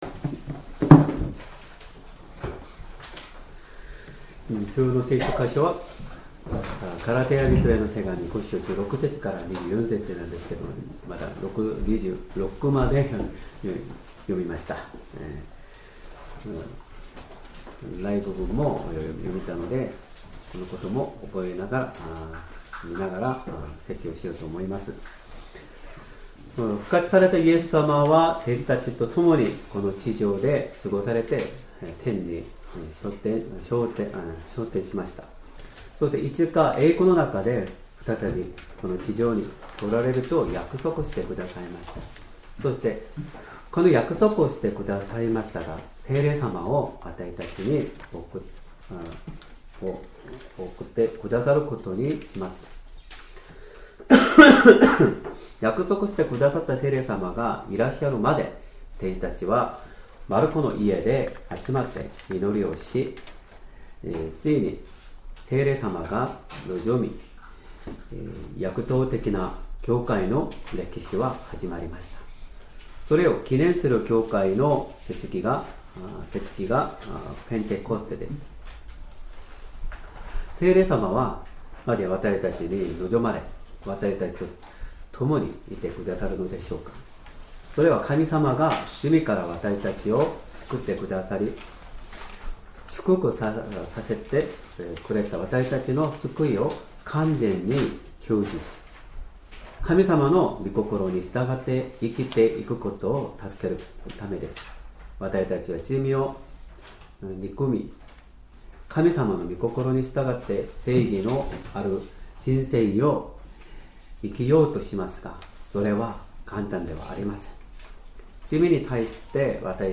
Sermon
2025年5月11日 主日礼拝 説教 「聖霊によって生きなさい 」 聖書 ガラテヤ人への手紙 5章 16-24節 5:16 私は言います。